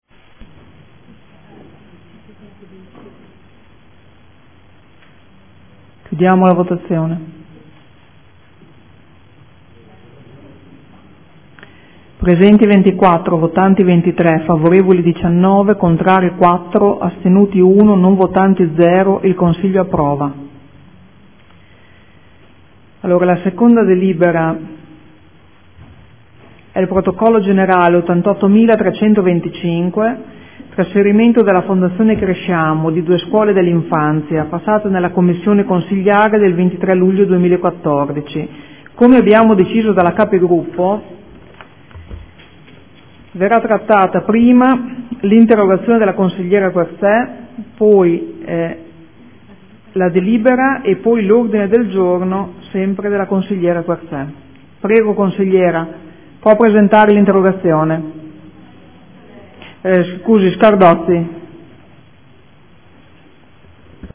Seduta del 31/07/2014.